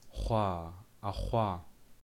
labzd voiceless uvular fricative
Labialized_voiceless_uvular_fricative.ogg.mp3